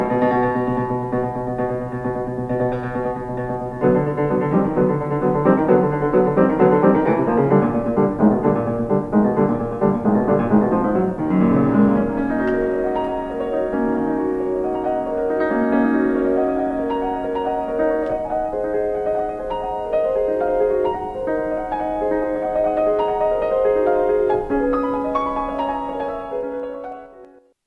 to-ccata.mp3